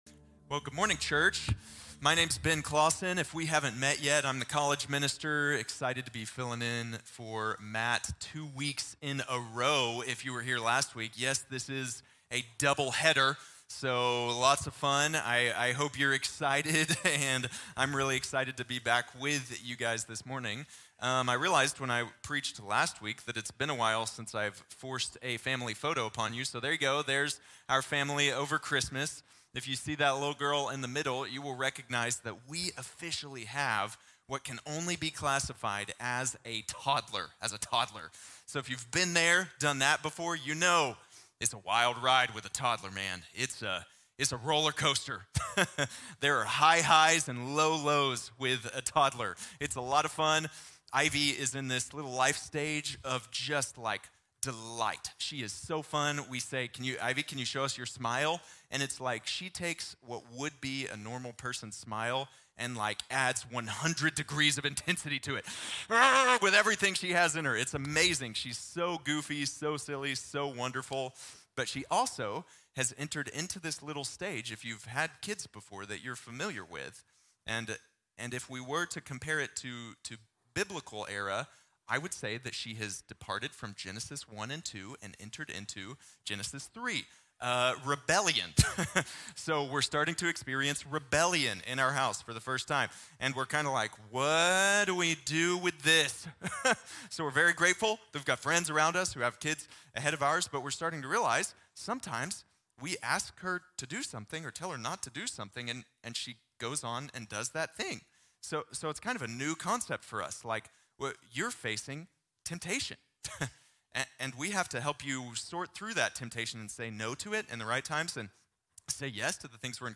Overcoming Temptation | Sermon | Grace Bible Church